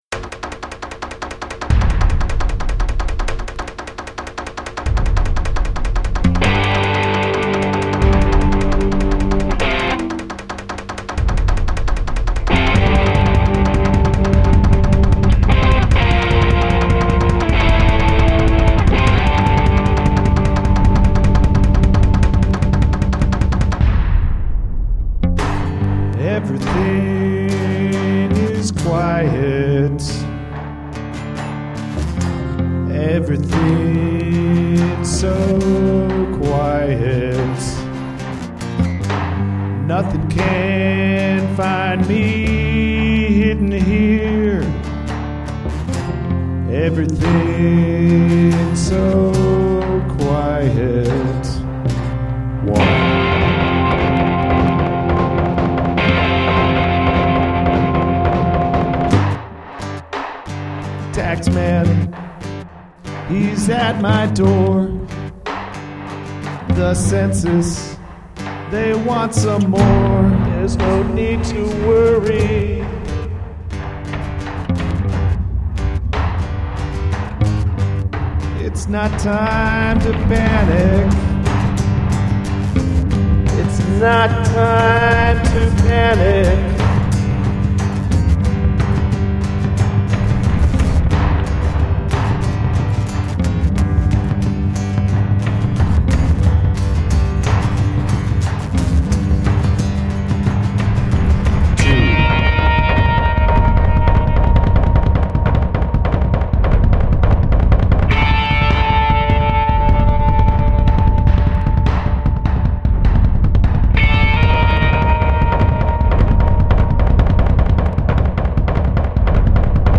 Song must include audible counting